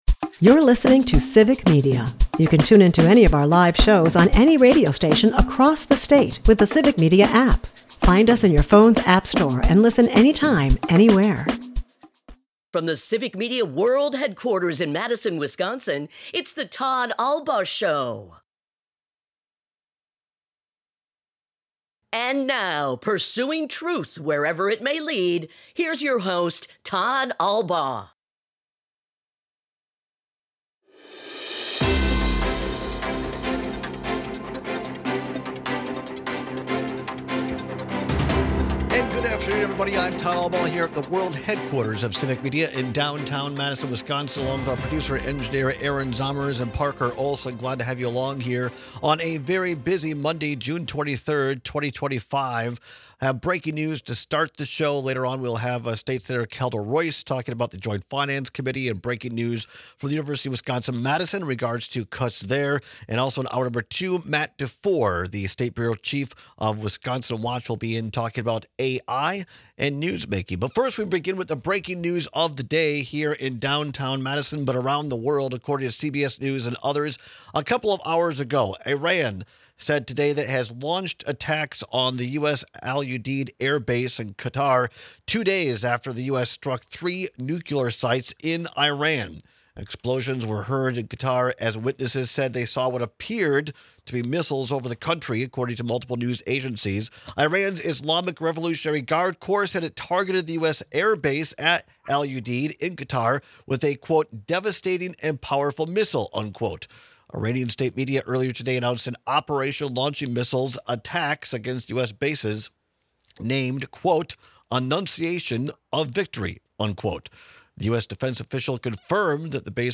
At the bottom of the hour, State Senator Kelda Roys returns for her weekly Joint Finance Committee update.